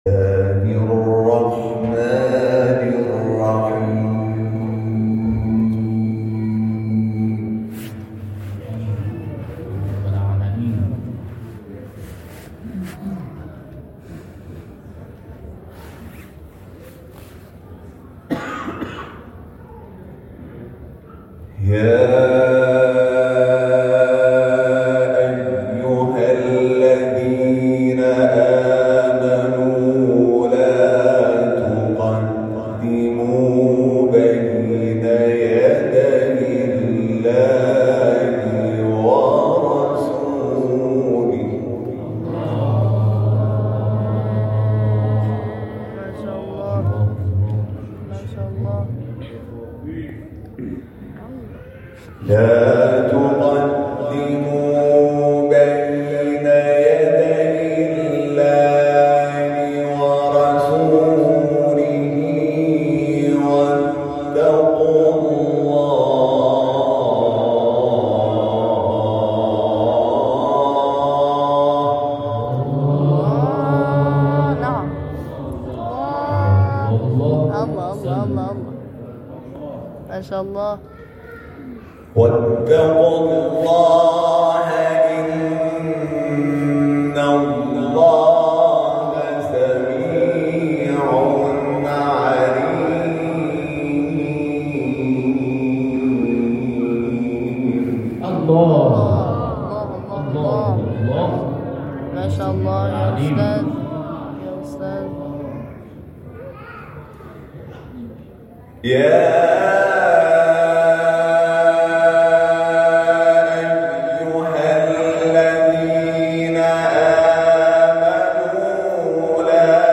تلاوت حمید شاکرنژاد، قاری بین‌المللی از آیات 1 تا 10 سوره «حجرات» در بیست و دومین کرسی تلاوت قاریان و حافظان استان یزد به مخاطبان ایکنا تقدیم می‌شود.